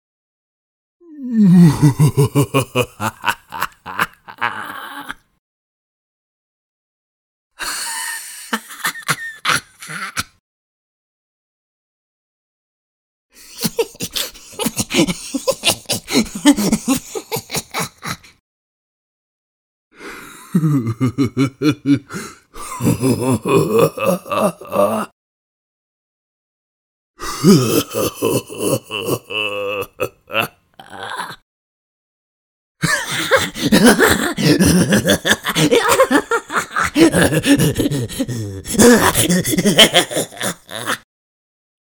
Series of evil laughs
crazy durchgedreht evil gelchter lachen laugh laughter lunatic sound effect free sound royalty free Funny